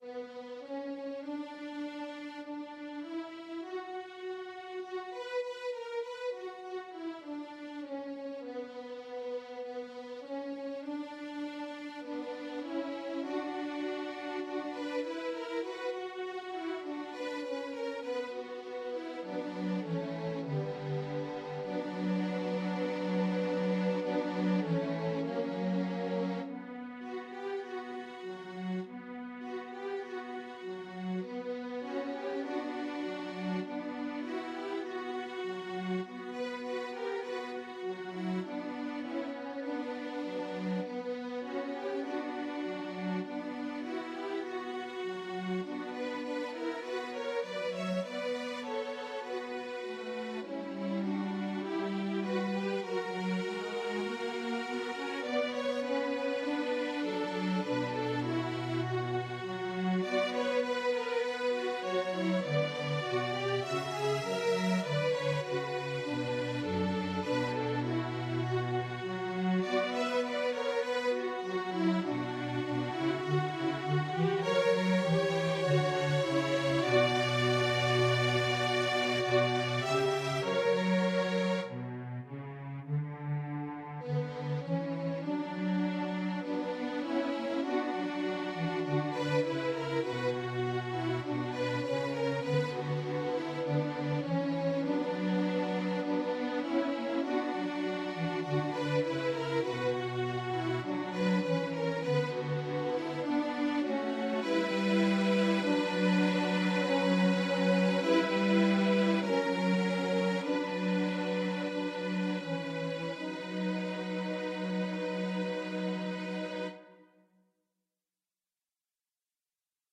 Folk and World